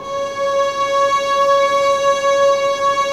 Index of /90_sSampleCDs/Roland - String Master Series/STR_Vlns Bow FX/STR_Vls Sordino